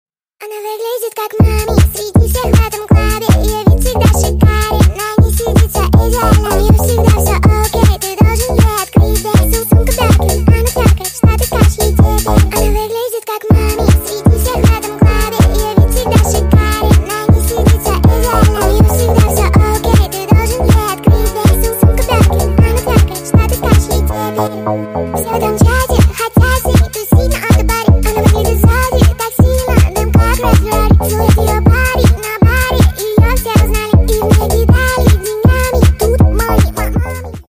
Весёлые Рингтоны
Рингтоны Ремиксы » # Поп Рингтоны